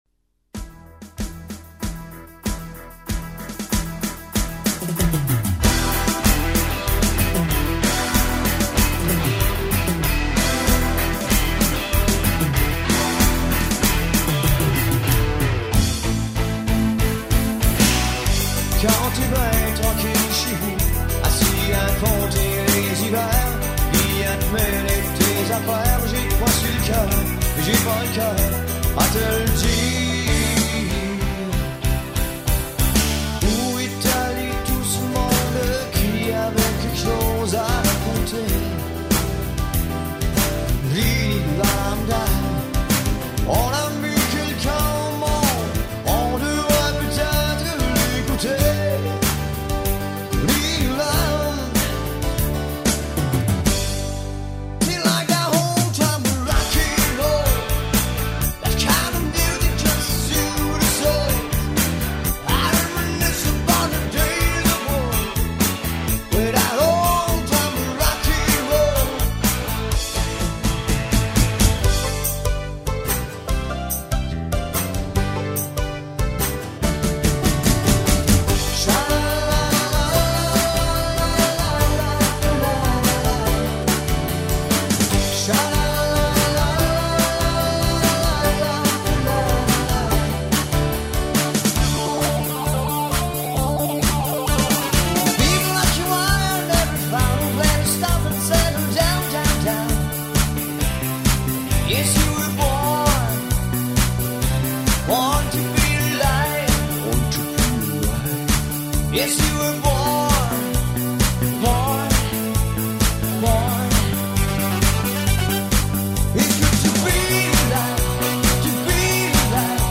Démo Audio
Musicien Chanteur Animateur Guitariste.